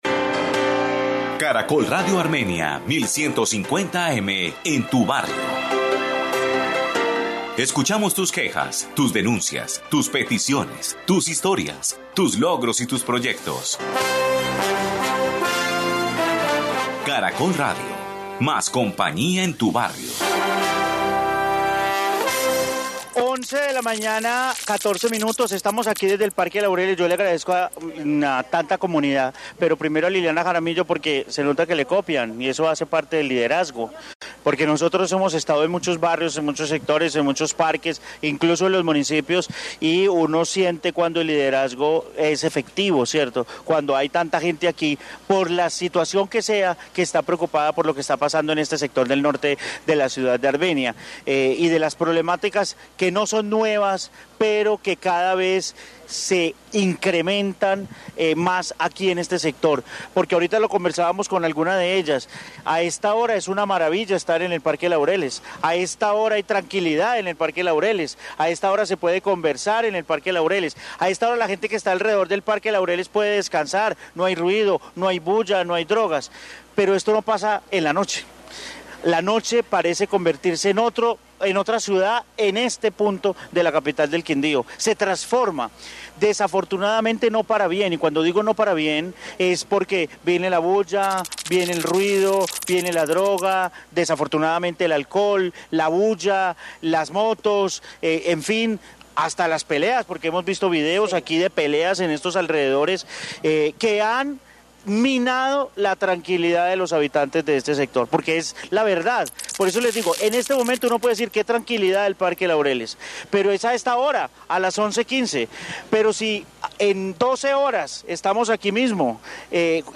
Caracol Radio Armenia originó el noticiero del mediodía desde este sector de la ciudad
Informe barrio Laureles Armenia